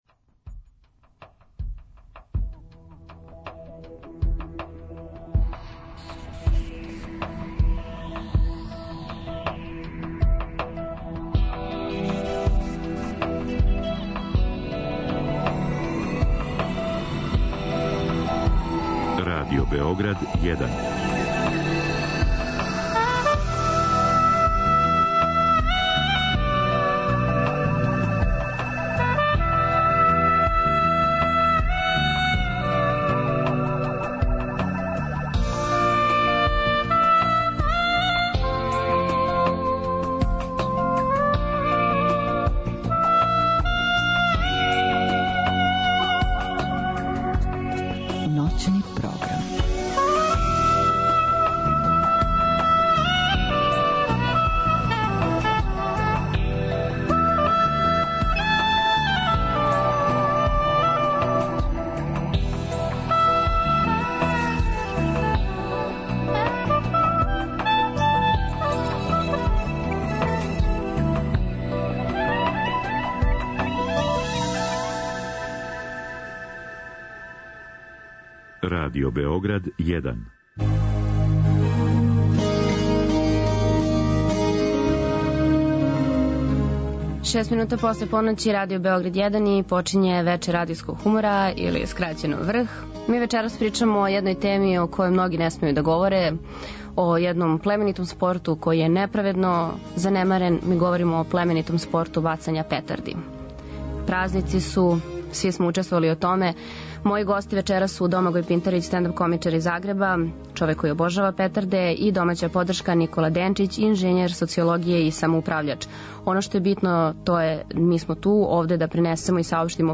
.Очекују нас и специјална укључења из Краљева и Сарајева.